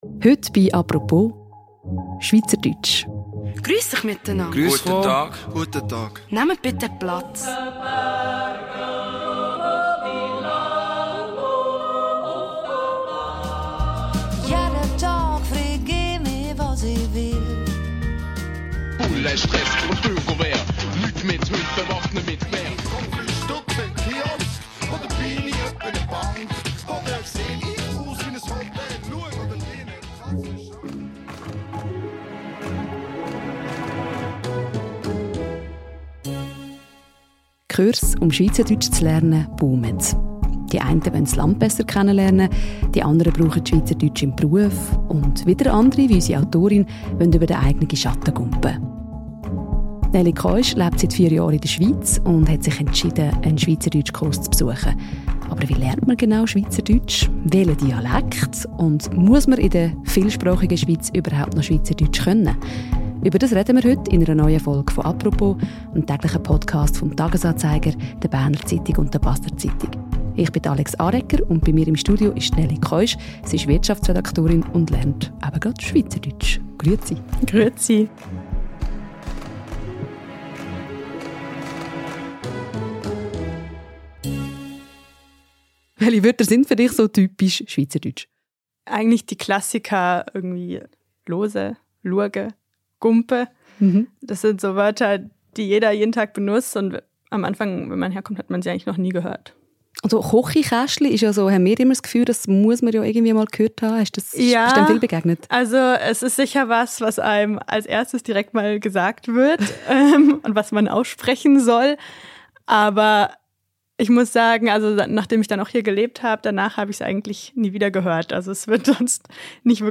Ein Blick ins Klassenzimmer.